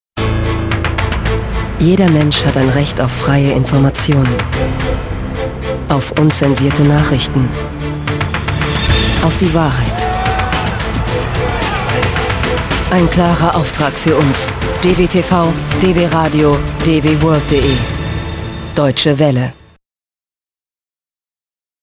Synchronsprecherin, Synchronschauspielerin, Sprecherin, Angelina Jolie, TV-Spots, Funkspots, Kinowerbung, Radiojingles, TV-Trailer, Dokumentationen,Videogames, PC-Spiele, Hörspiele
Sprechprobe: Sonstiges (Muttersprache):
german female voice over artist, dubbingvoice of Angelina Jolie, Maria Bello, Jennifer Connelly, Vera Farmiga and Rachel Weisz etc. TV-Commercials, Stationvoice, Documentation, Radioplays, Videogames,